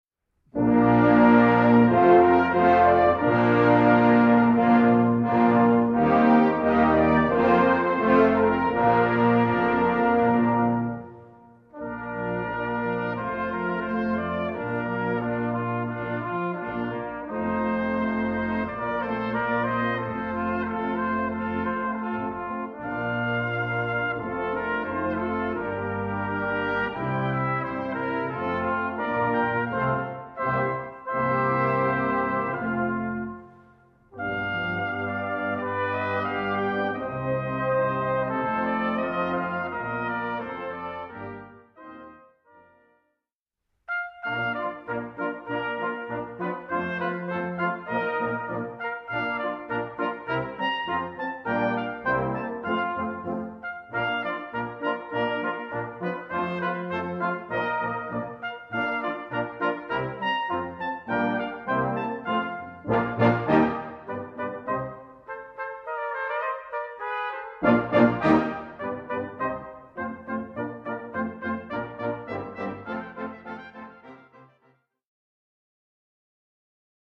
Gattung: Klarinetten oder Cornet Duett
Besetzung: Blasorchester